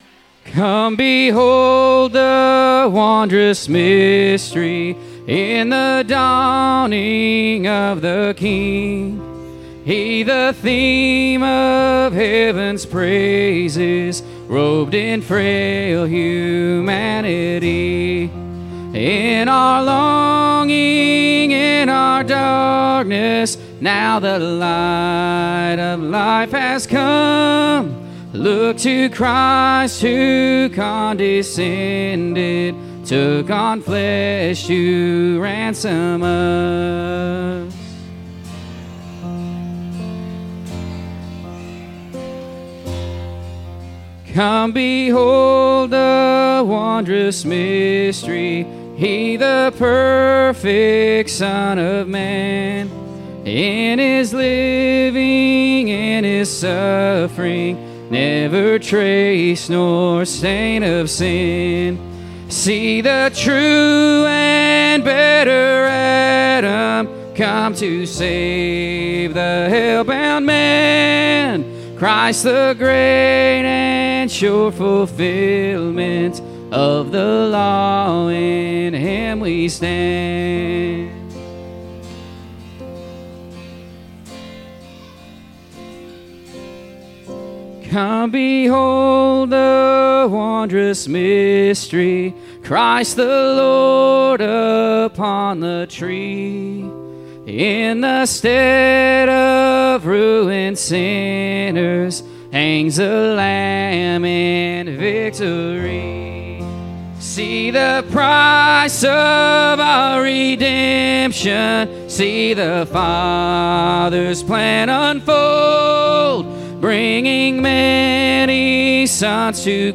Waldo Candlelight Service